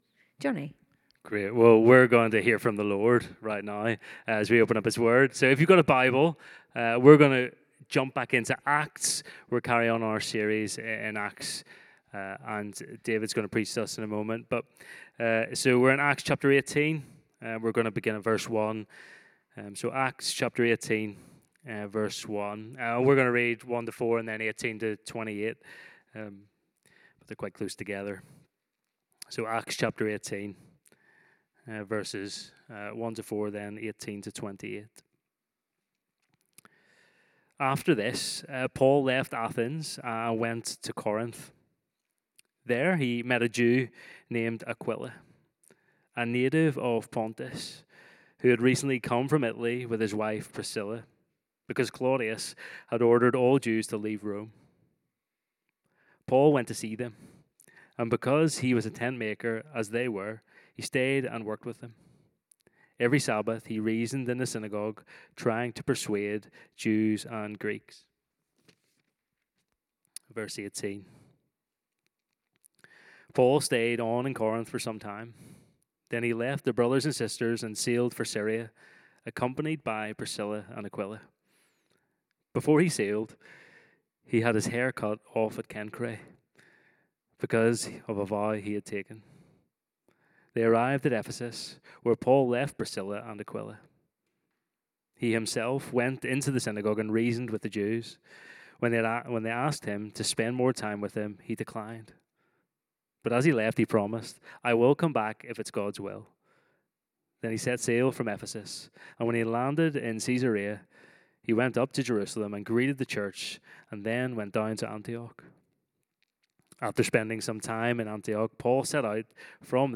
Gathering Service
Sermons